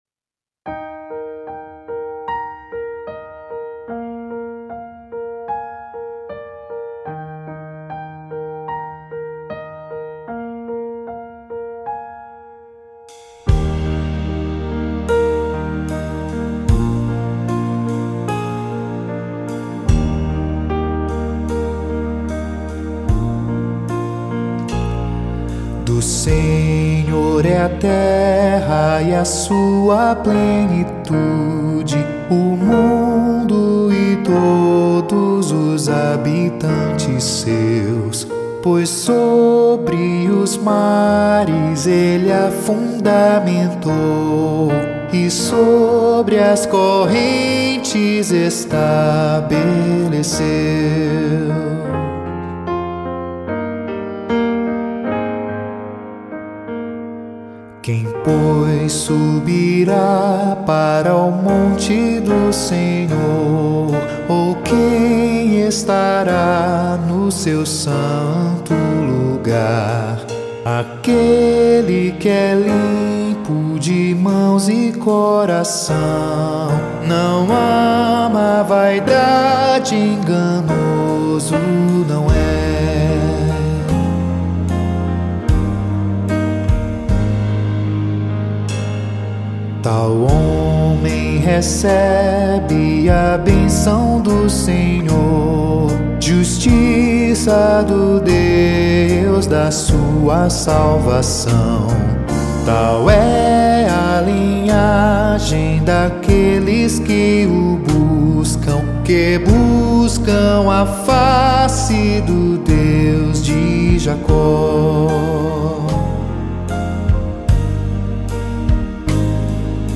salmo_24B_cantado.mp3